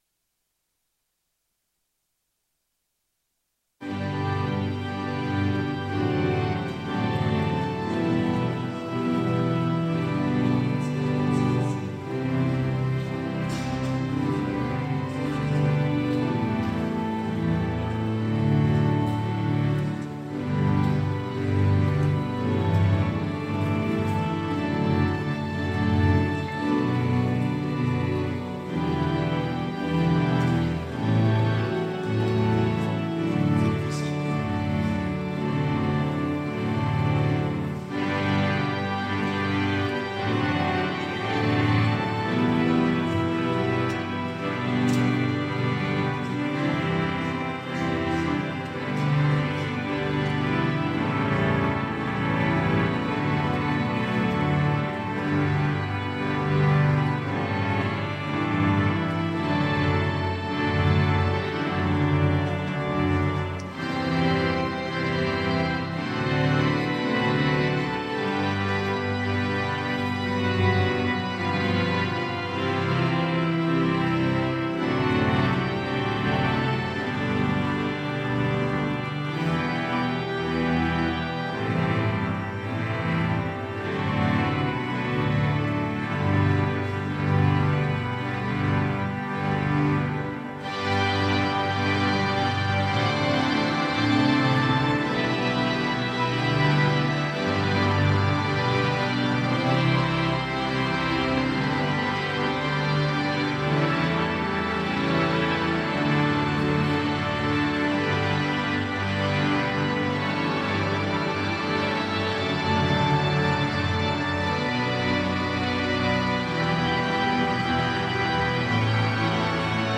Culte à l’Oratoire du Louvre
Orgue